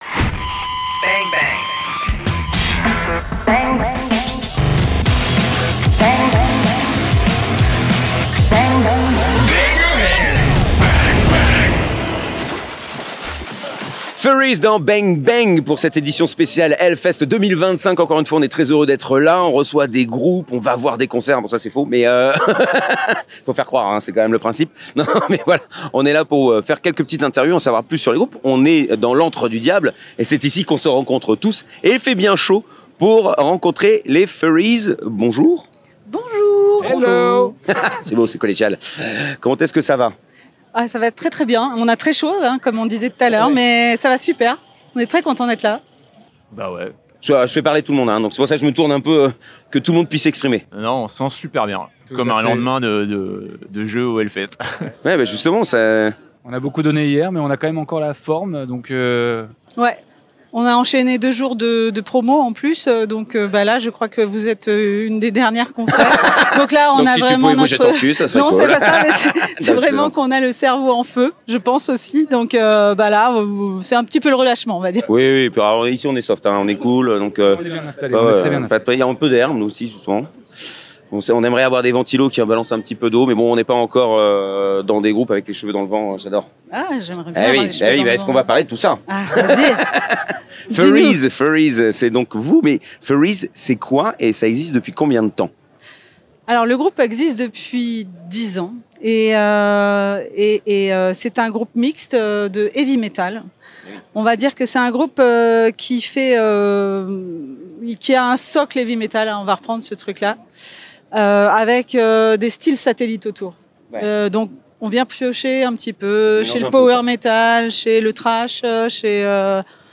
Voilà encore une édition de HELLFEST accomplie ! Et nous avons fait 23 interviews !